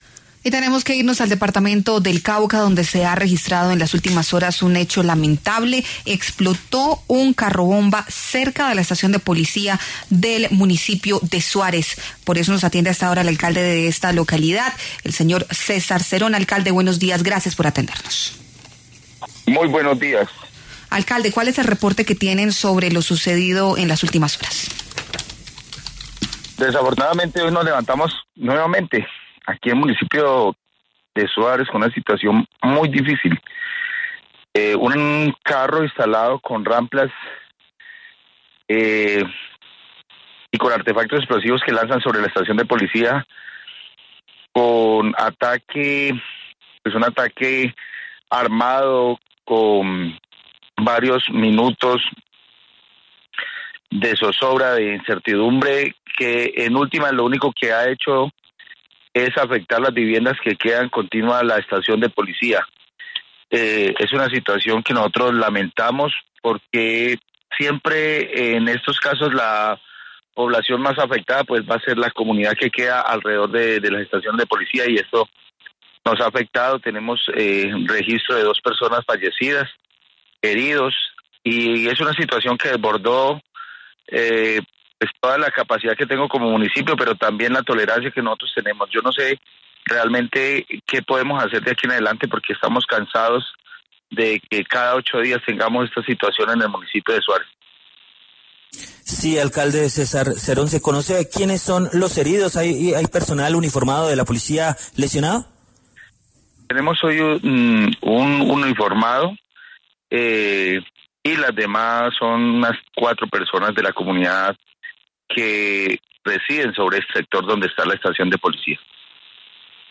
En diálogo con La W, el alcalde de Suárez, Cauca, César Cerón, confirmó que el atentado con artefactos explosivos contra la estación de Policía del municipio dejó un saldo de dos personas muertas y varios heridos.